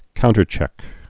(kountər-chĕk)